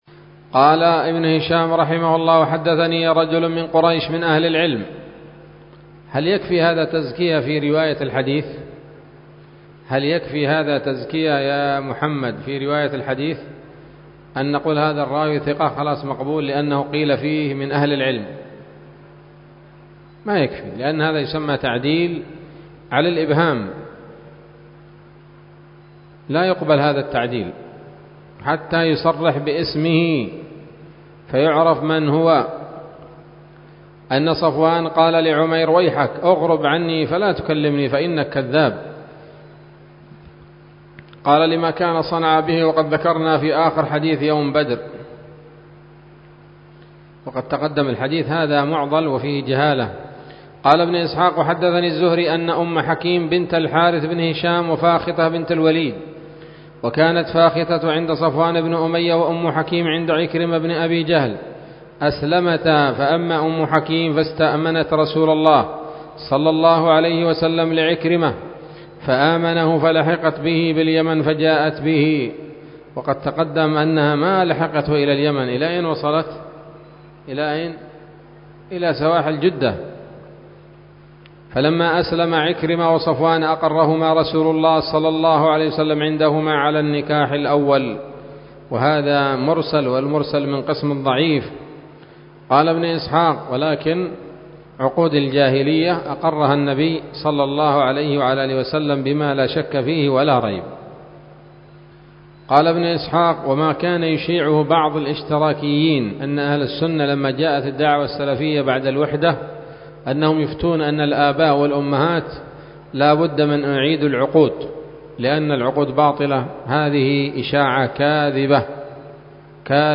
الدرس السادس والستون بعد المائتين من التعليق على كتاب السيرة النبوية لابن هشام